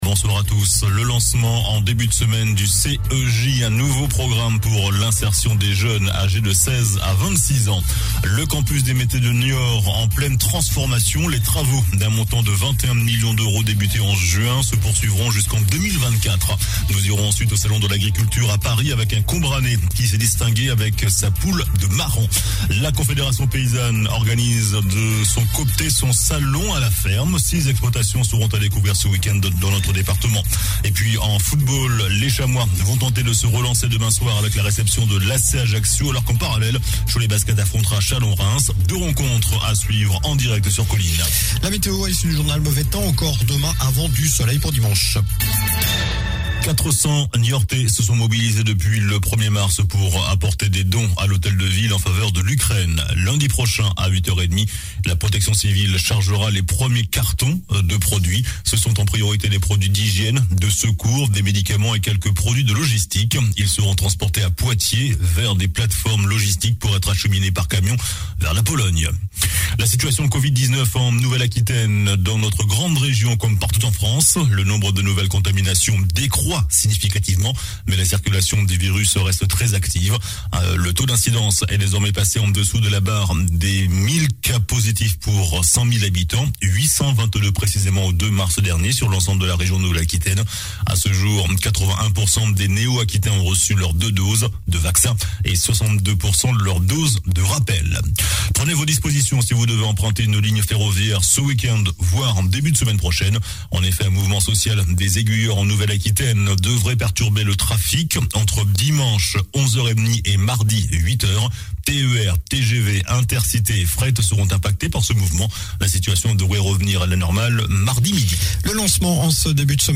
Journal du vendredi 04 mars (soir)